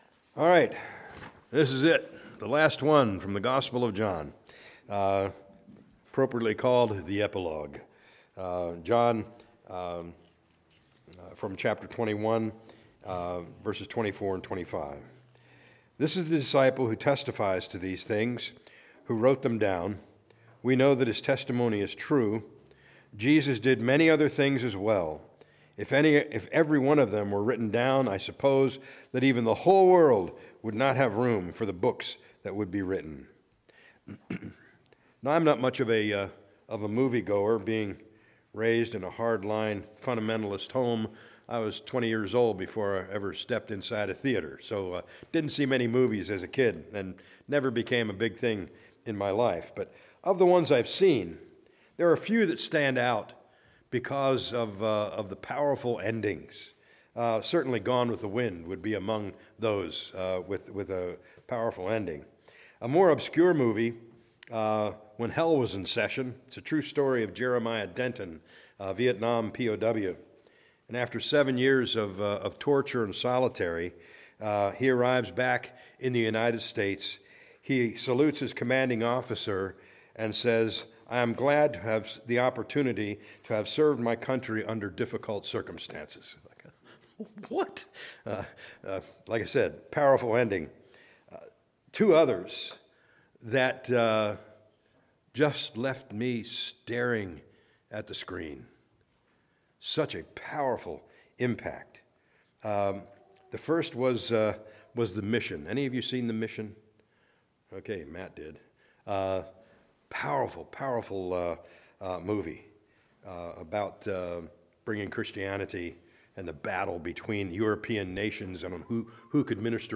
A message from the series "John."